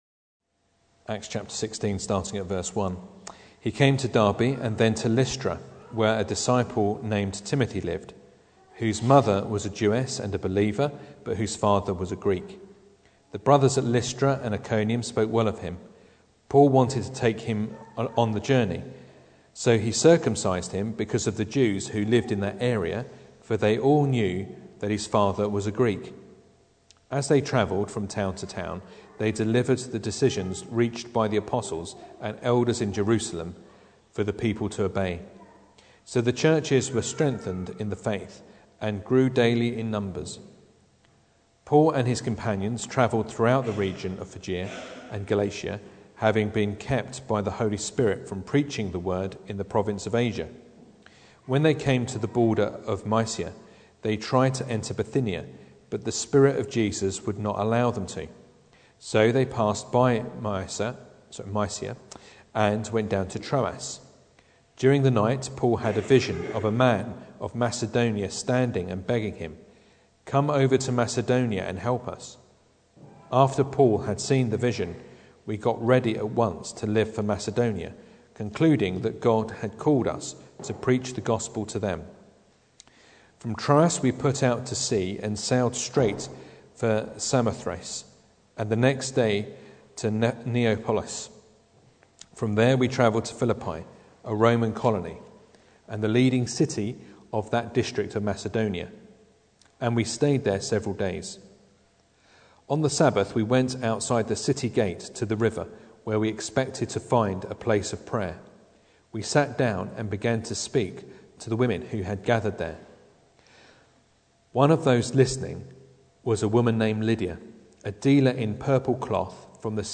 Acts 16 Service Type: Sunday Evening Bible Text